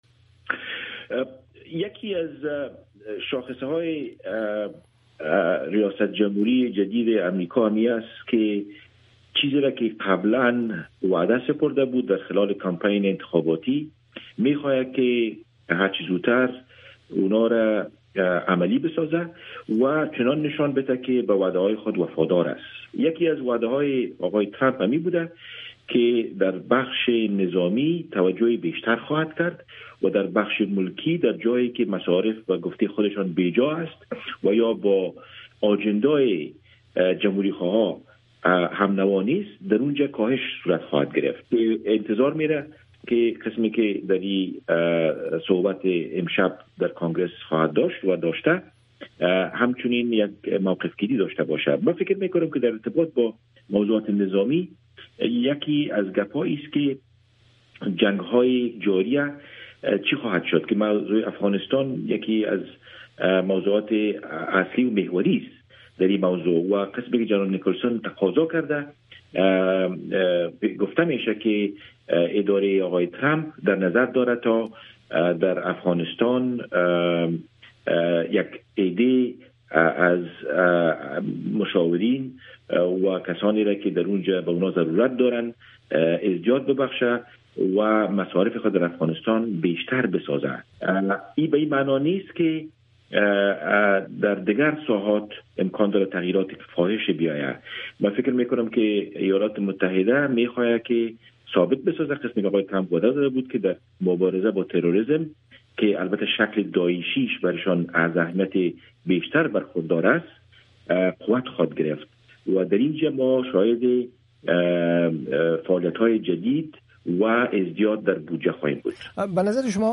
مصاحبه ها